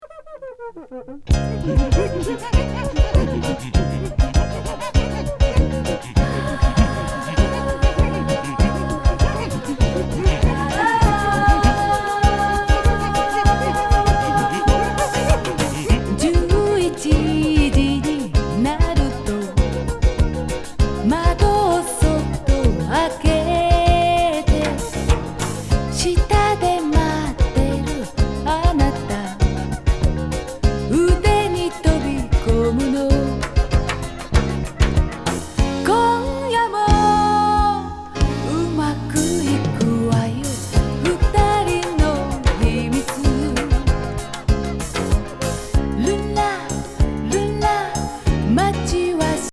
コーラス入りソフト・サンバ